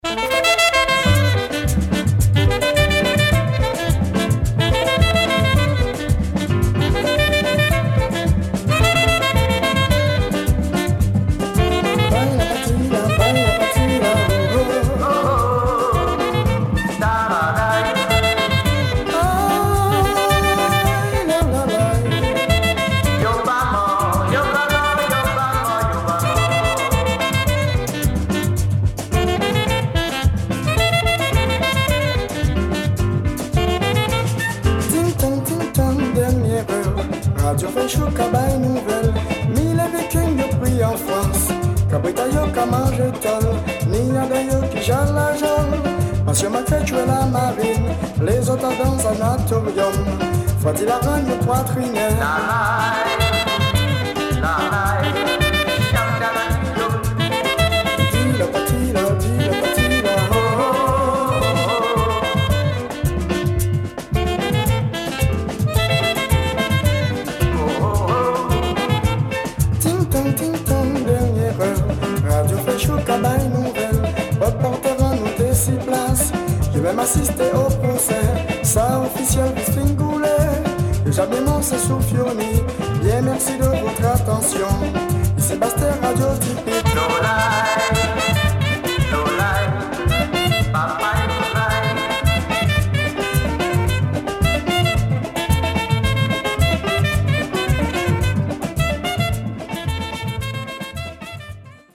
Wonderful caribbean sound…